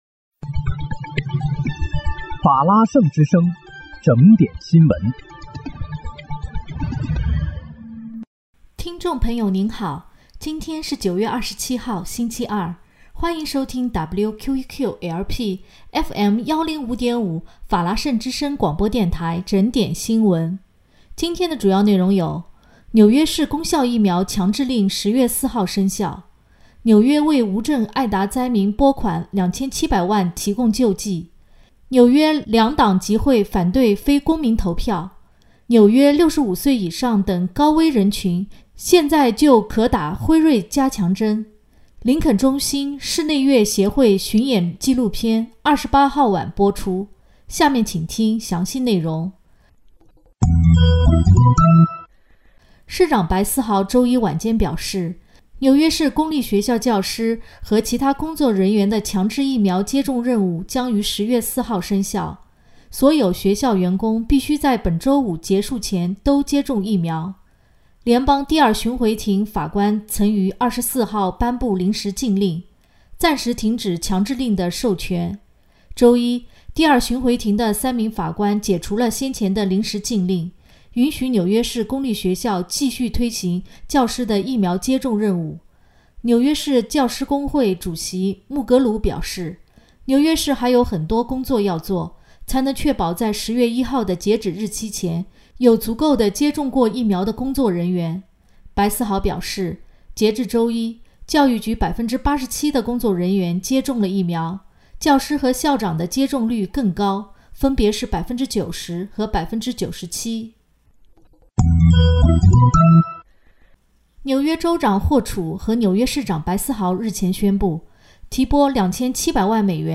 9月28日（星期二）纽约整点新闻